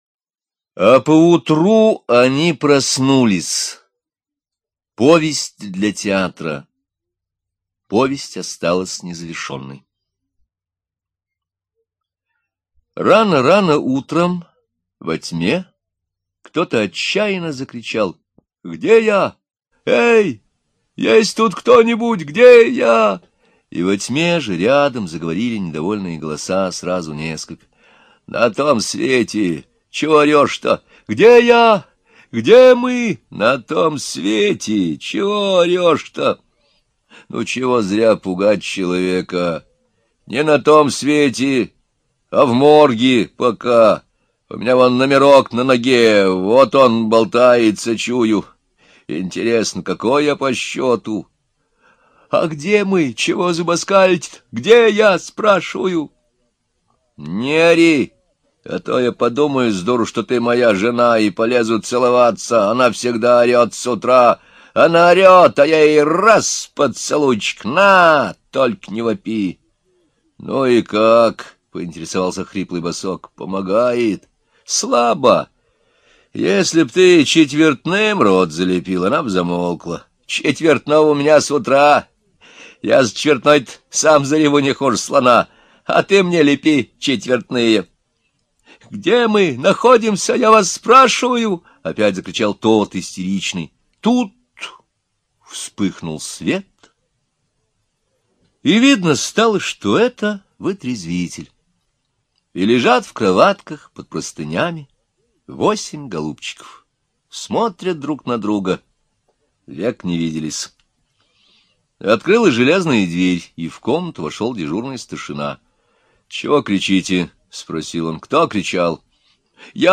А по утру они проснулись... Шукшин аудио книгу слушать, А по утру они проснулись... Шукшин аудио книгу слушать бесплатно